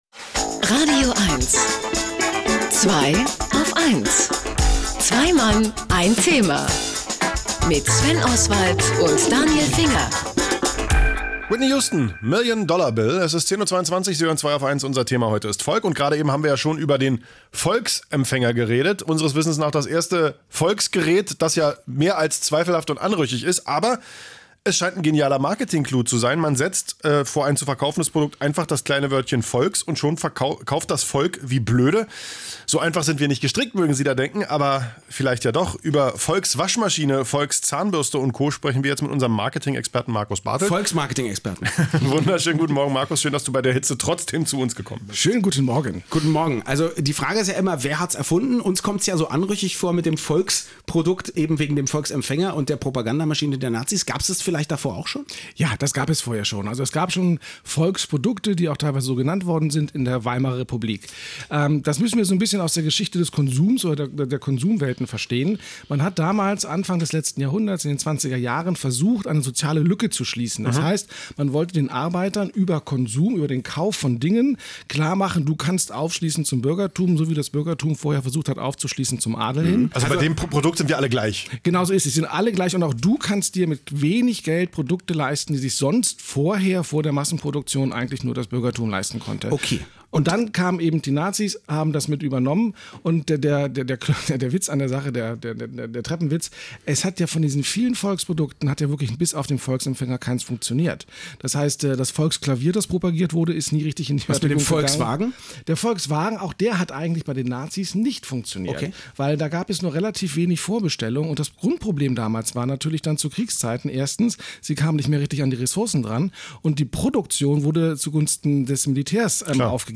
Was es damit auf sich hat, durfte ich heute bei den coolen Mderatoren von „Zweiaufeins“ im wohlklimatisierten radioeins-Studio zu erzählen: